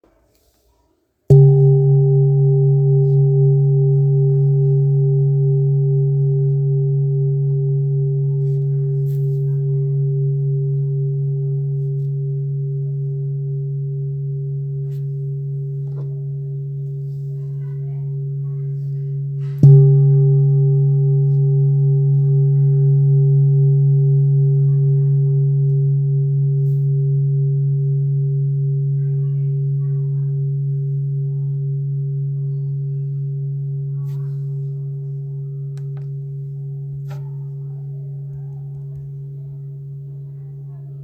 Kopre Singing Bowl, Buddhist Hand Beaten, Antique Finishing
Material Seven Bronze Metal